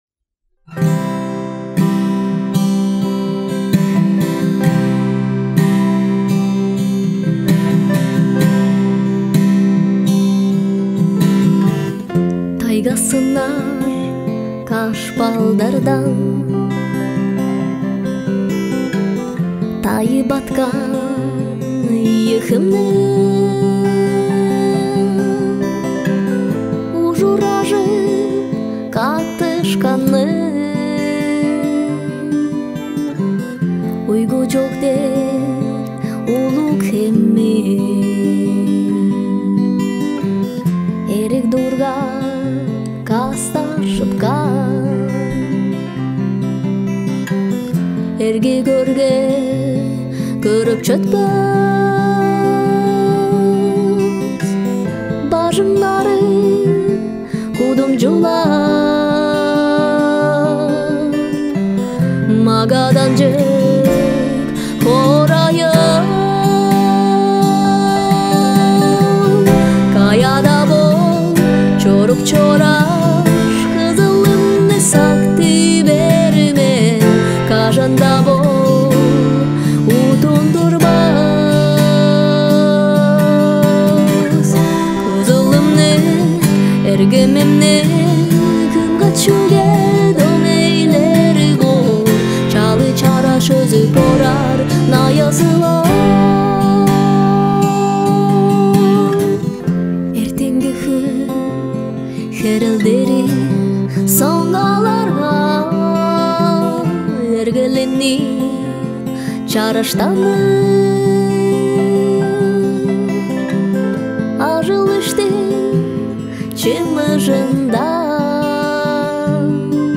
девушка поет
женская версия
тувинская песня